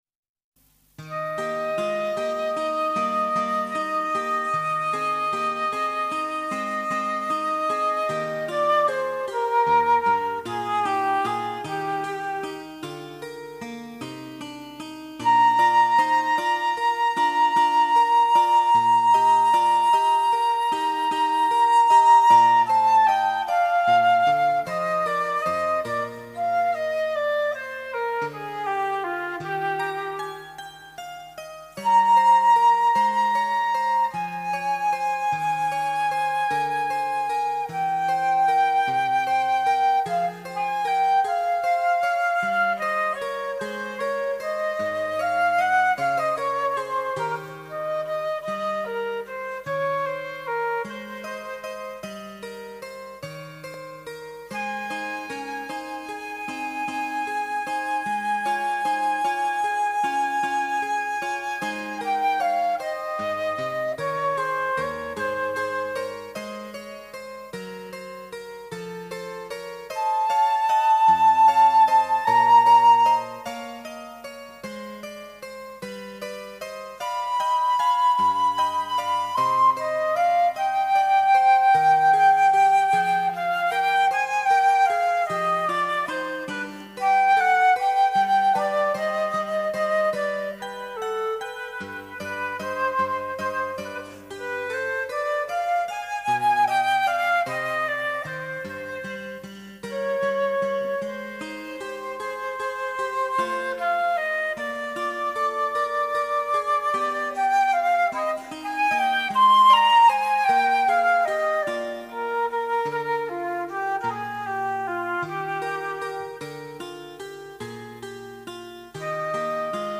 昔公開した私の演奏です。
Flute Sonata G moll BWV1020 II (J. S. Bach)